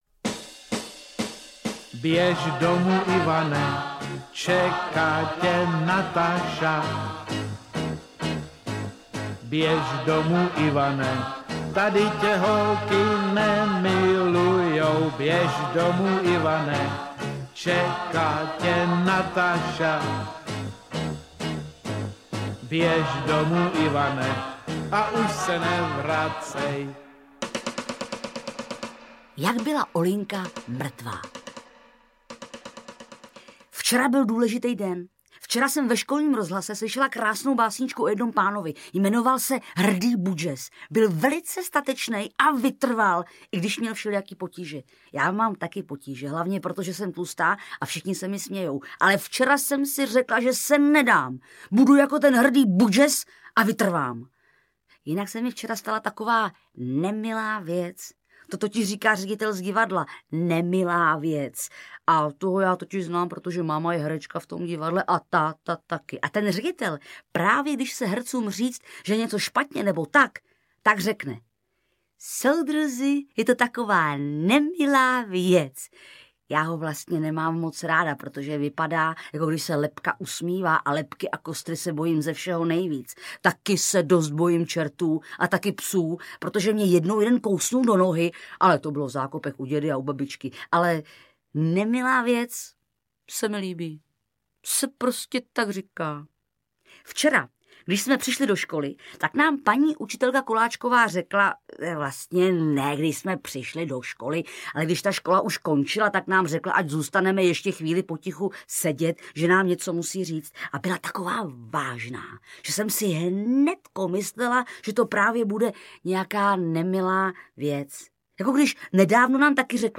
Audiobook
Read: Barbora Hrzánová